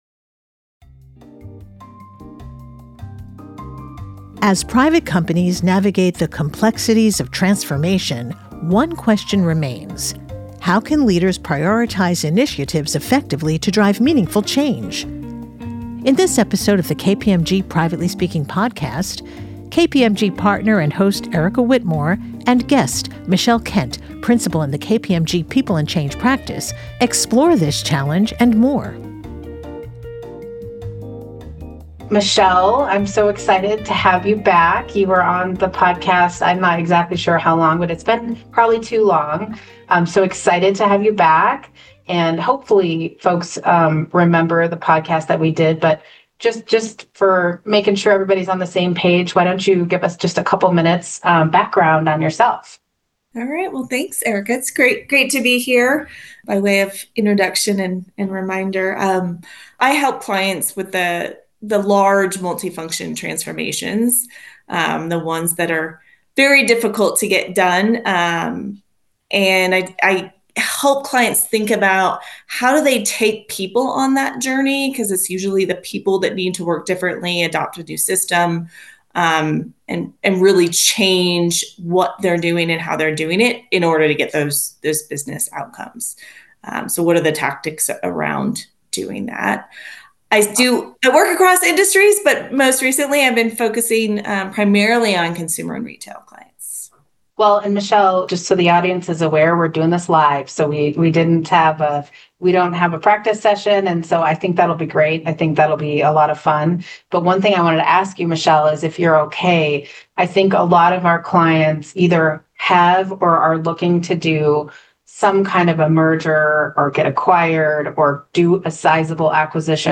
This thought-provoking conversation offers insights on the following topics: Navigating Multiple Transformations : Discover how to prioritize initiatives effectively when faced with multiple simultaneous transformations, and learn strategies to avoid overwhelming your team.